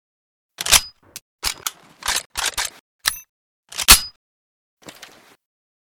sks_reload.ogg